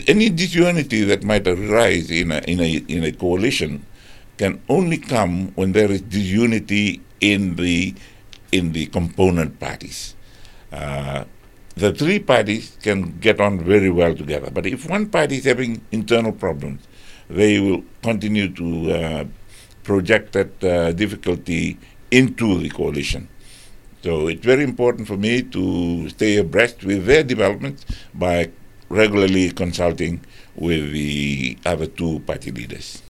Prime Minister Sitiveni Rabuka has acknowledged the challenges faced by the coalition government during an interview on Radio Fiji One’s “Na Noda Paraiminista” program.